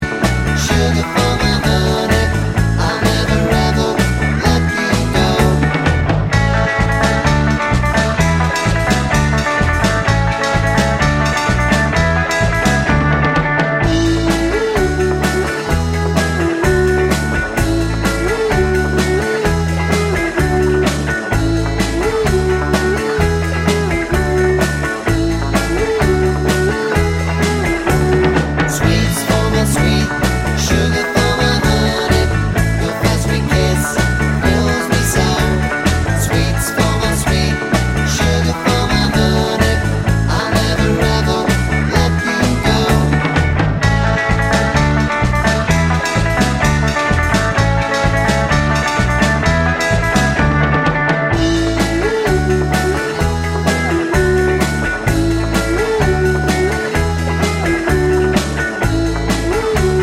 Three Semitones Down Pop (1960s) 2:30 Buy £1.50